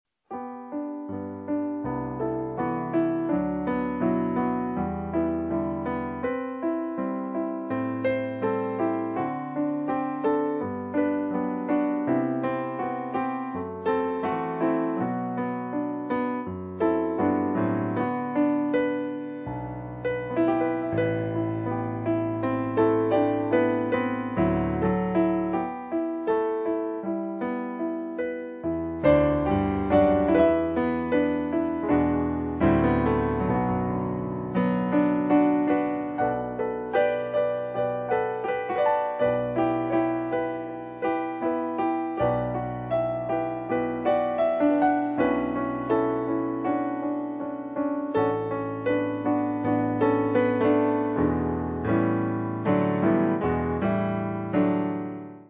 これは新録音ではなく、２年前の練習音源の断片的な録音であるが、今後より完成度の高い形で録音を残したい曲の一つである。